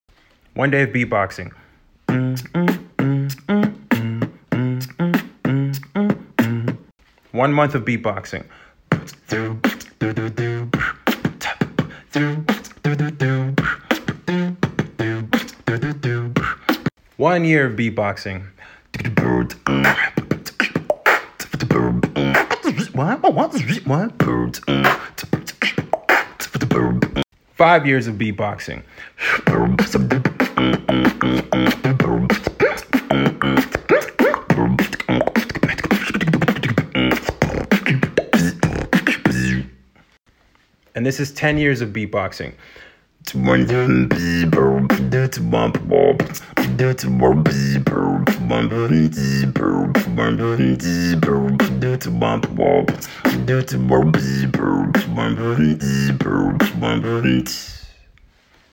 evolution of beatboxing sound effects free download